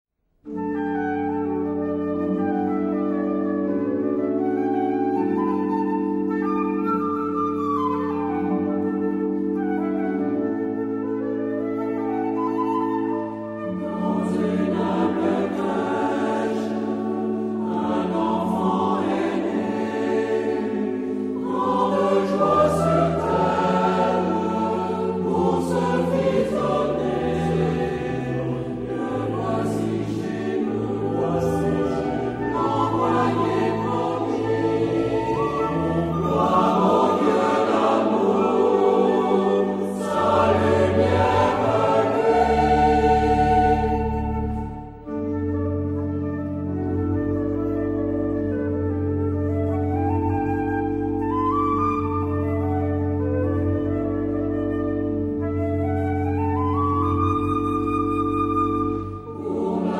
Genre-Style-Forme : Sacré ; noël ; Hymne (sacré)
Caractère de la pièce : vivant
Type de choeur : SATB  (4 voix mixtes )
Instruments : Flûte (1) ; Tambour (ad lib) ; Orgue (1)
Tonalité : ré majeur